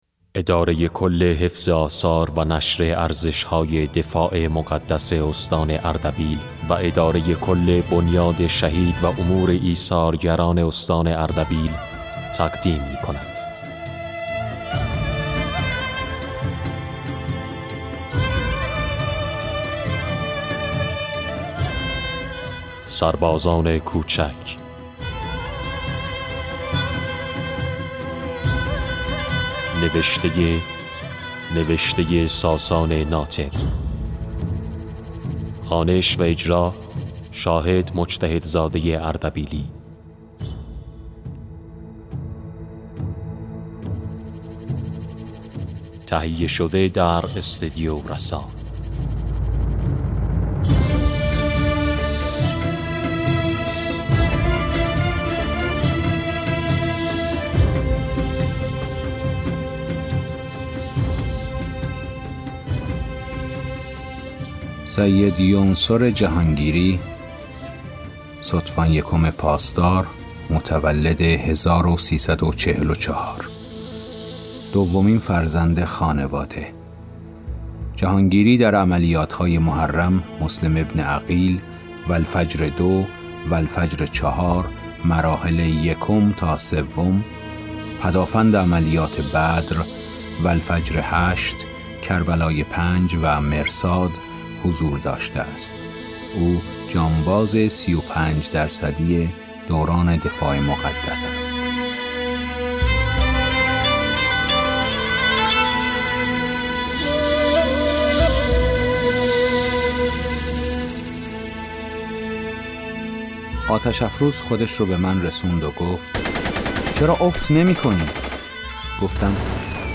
کتاب گویای «سربازان کوچک»/ دانلود